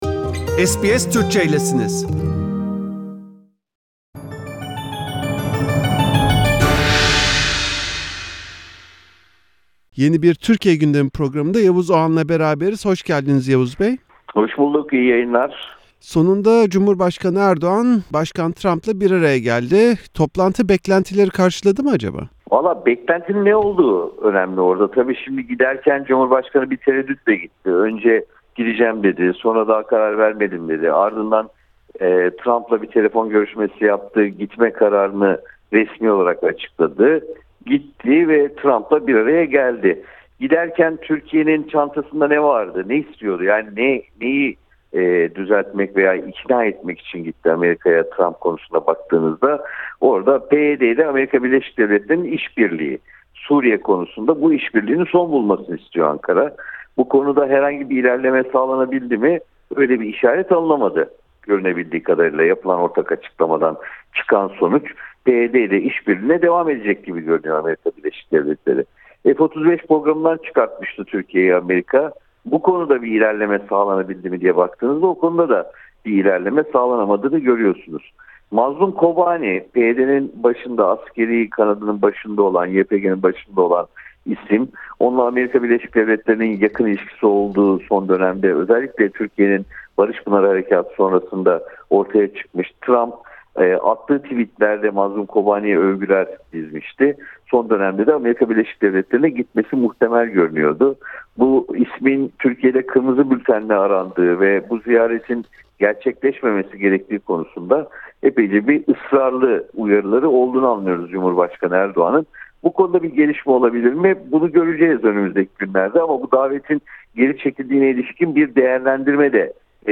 Bu haftaki Türkiye Gündemi bölümünde, gazeteci Yavuz Oğhan'la, Cumhurbaşkanı Erdoğan'la Ameriklna Başkanı Trump'ın 13 Kasım'da gerçekleştirdiği toplantıyı konuştuk. Ayrıca, İŞİD üyelerinin sınırdışı edilmesi ve Ahmet Altan'ın tekrar hapsedilmesi hakkındaki değerlendirmelerini dinledik.